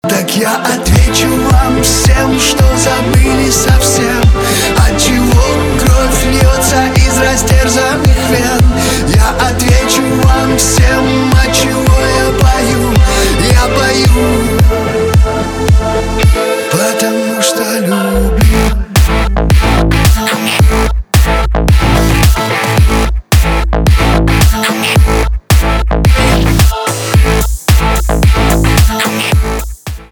поп
басы , чувственные
электроника